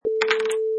Clips: Dice Roll 1
Roll of the dice
Product Info: 48k 24bit Stereo
Category: Amusement and Games / Dice
Try preview above (pink tone added for copyright).
Dice_Roll_1.mp3